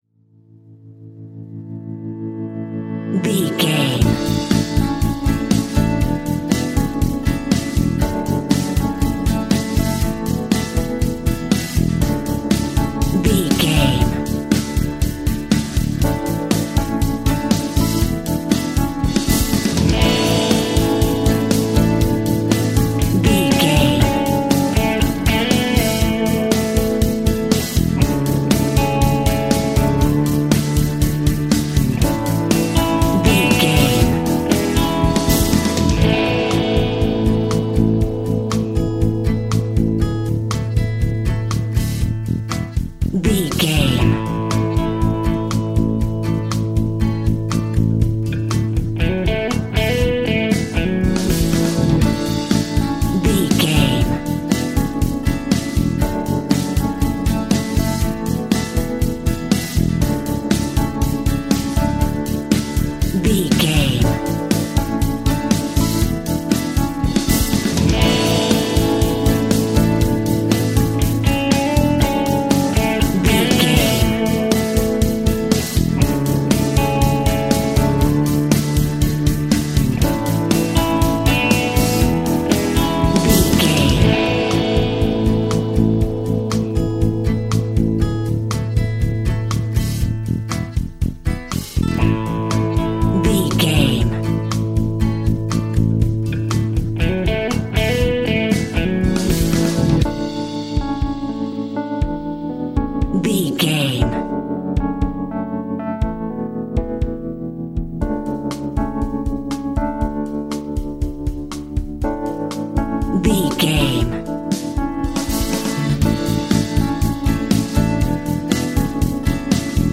Ionian/Major
pop rock
energetic
uplifting
upbeat
groovy
guitars
bass
drums
piano
organ